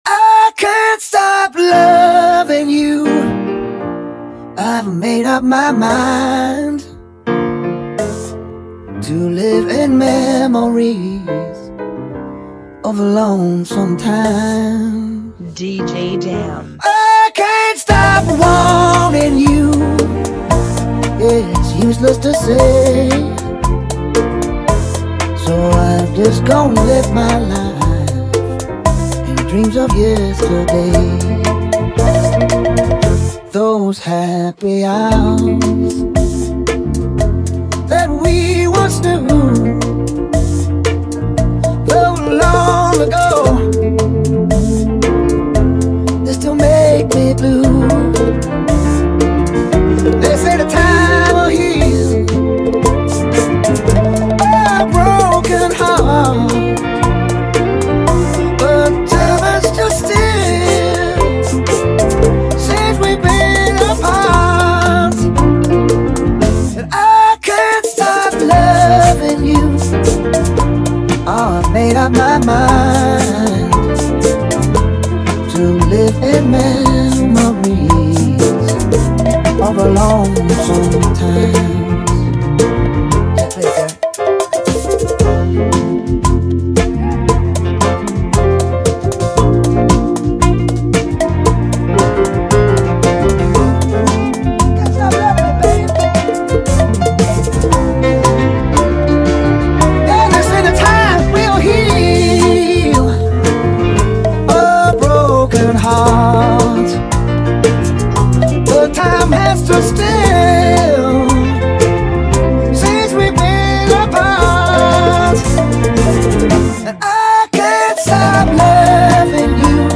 115 BPM
Genre: Bachata Remix